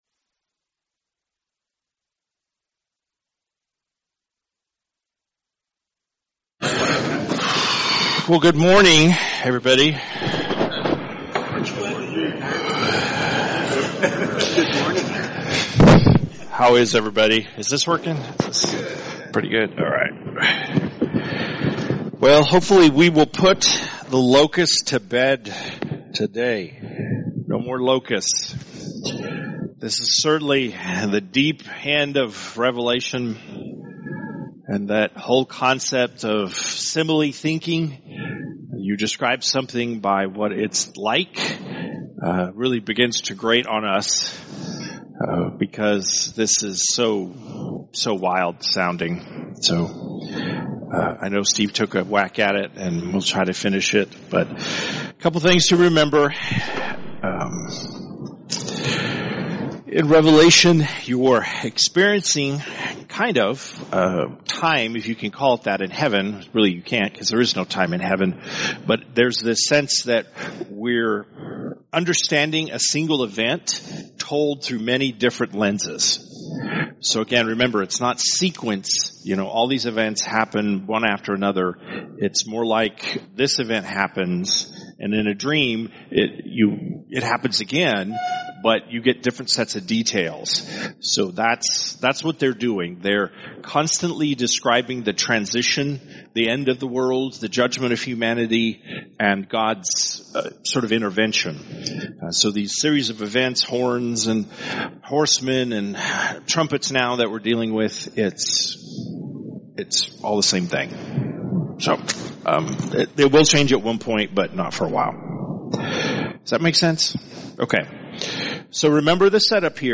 Men’s Breakfast Bible Study 9/10/24